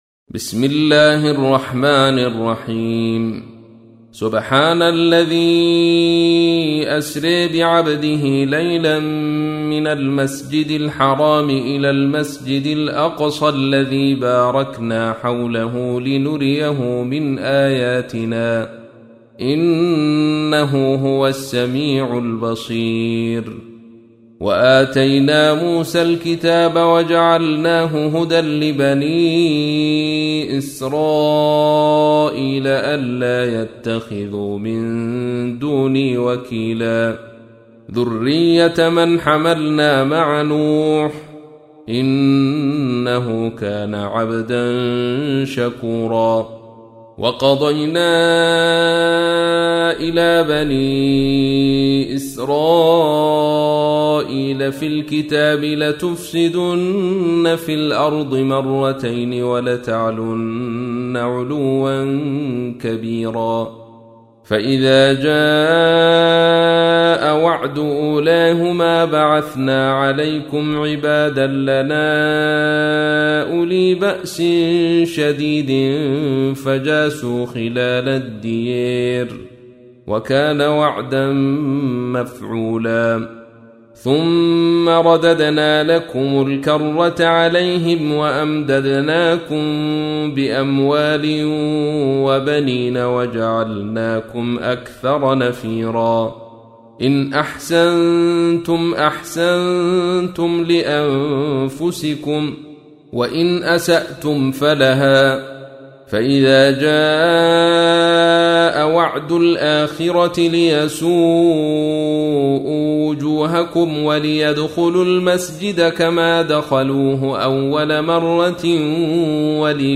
تحميل : 17. سورة الإسراء / القارئ عبد الرشيد صوفي / القرآن الكريم / موقع يا حسين